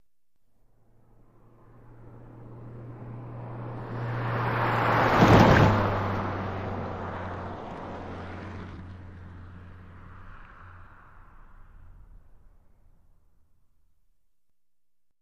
Car Tires Over Railroad Tracks 4x